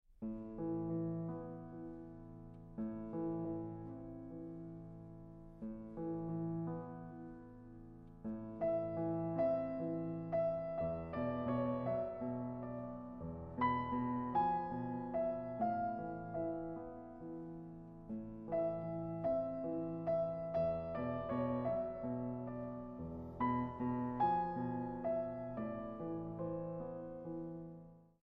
Piano
Trackdown Studios